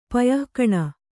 ♪ payah kaṇa